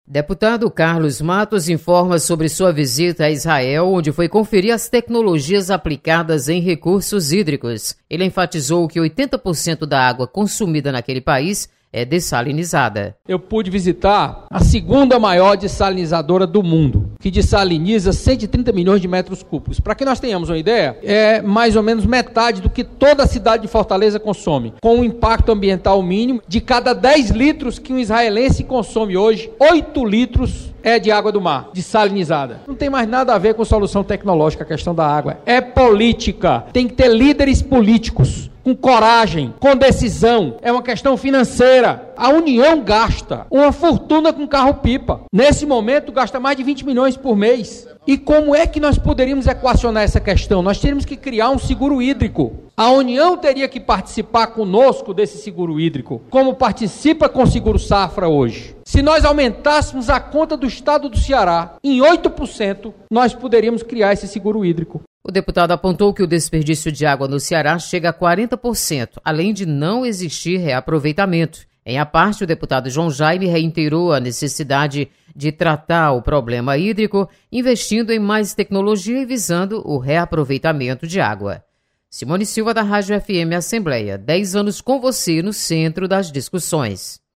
Deputado Carlos Matos relata experiência implantada em Israel para o reaproveitamento da água.